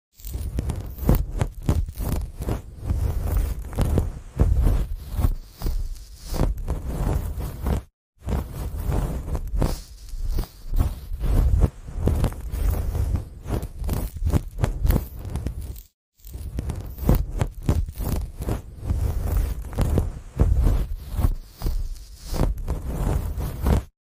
✨ The sound of order turning into serene beauty.